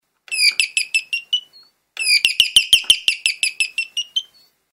Звуки пения соловья
Эти чистые, мелодичные звуки помогут вам расслабиться, найти вдохновение или просто насладиться красотой природы.
Звук дверного звонка с голосом соловья